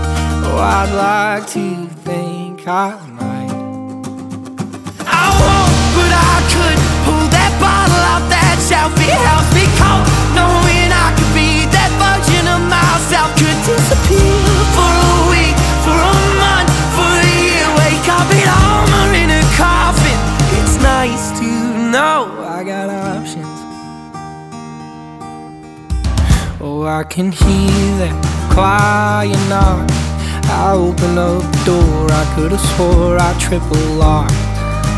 Country Alternative
Жанр: Альтернатива / Кантри